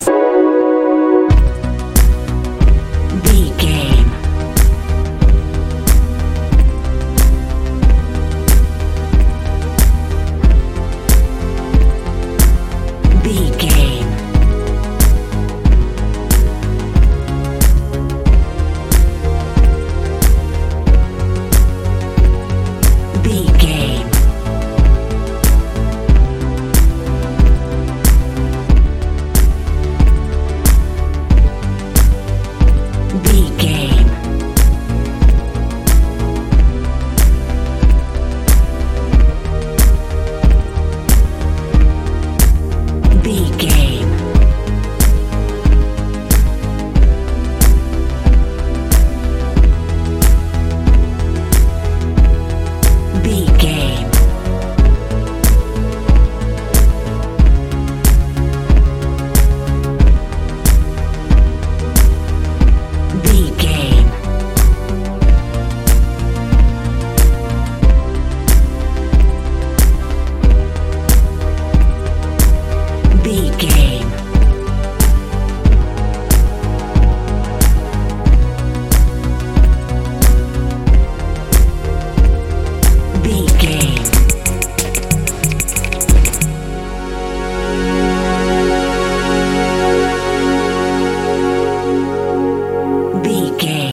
dance feel
Ionian/Major
light
mellow
piano
synthesiser
bass guitar
drums
80s
90s
strange
suspense